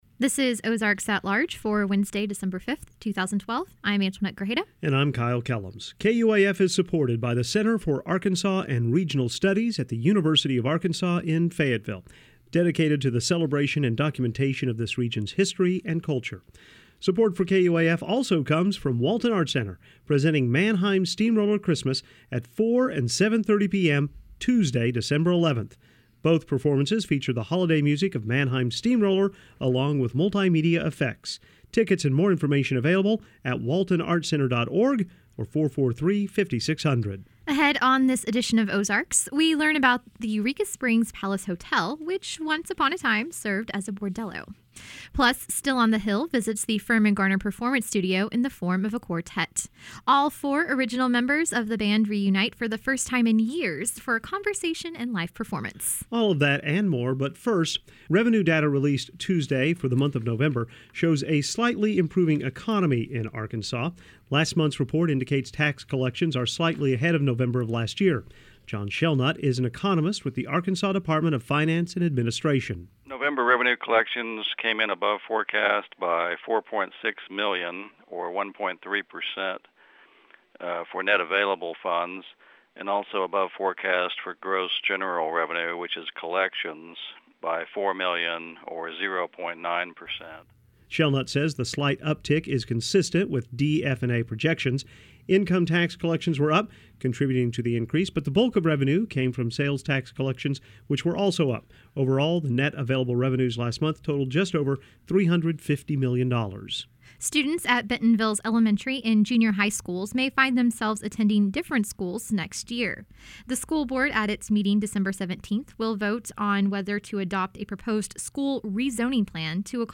Audio: oalweb120512.mp3 Ahead on this edition of Ozarks, we learn about the Eureka Springs' Palace Hotel, which once upon a time served as a bordello. Plus, Still on the Hill visits the Firmin-Garner Performance Studio in the form of a quartet. All four original members of the band reunite for the first time in years for a conversation and live performance.